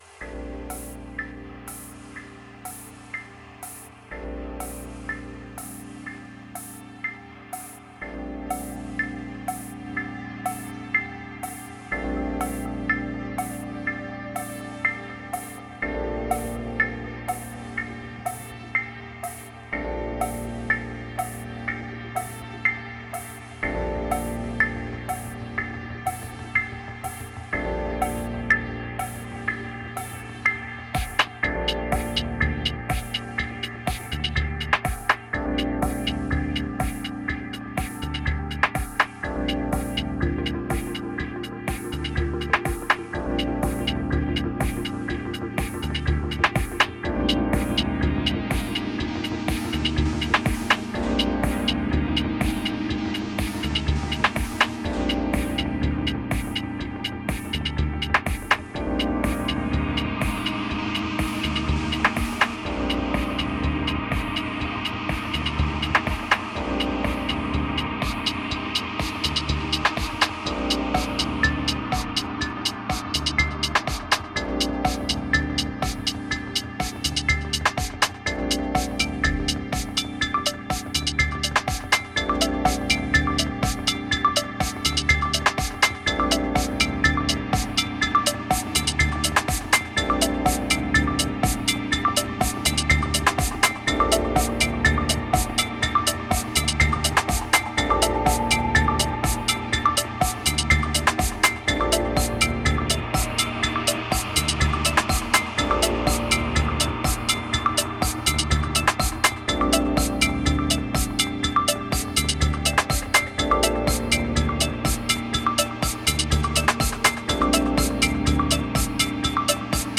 3352📈 - 75%🤔 - 123BPM🔊 - 2016-04-04📅 - 611🌟